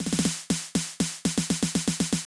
ITA Snare Roll.wav